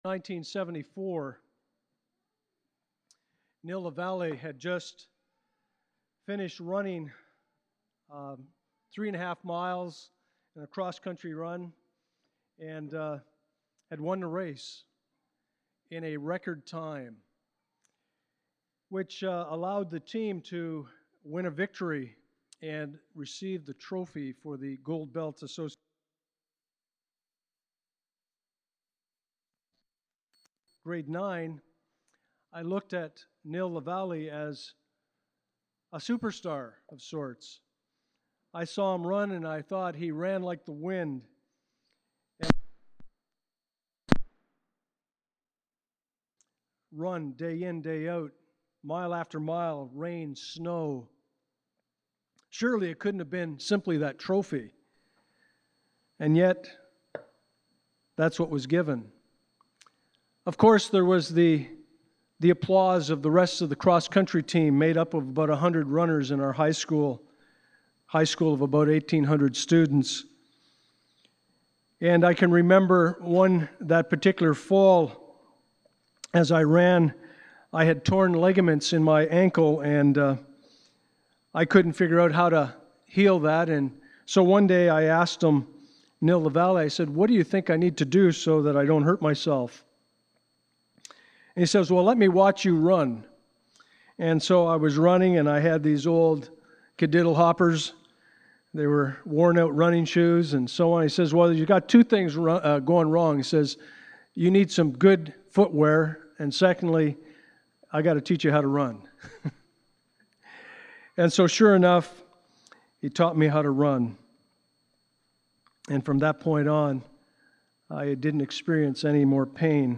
Passage: 2 Corinthians 5:12-21 Service Type: Sunday Morning